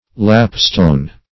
lapstone - definition of lapstone - synonyms, pronunciation, spelling from Free Dictionary
Search Result for " lapstone" : The Collaborative International Dictionary of English v.0.48: Lapstone \Lap"stone`\, n. A stone for the lap, on which shoemakers beat leather.